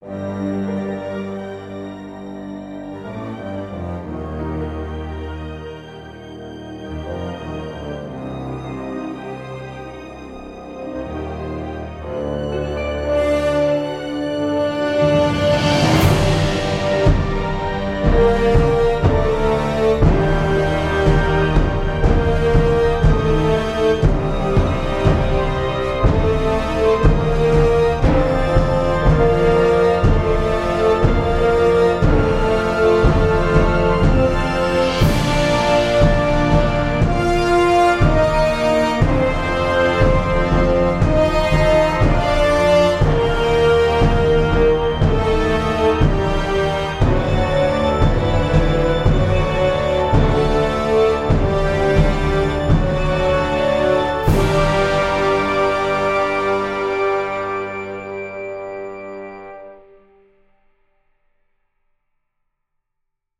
Seven varations for orchestra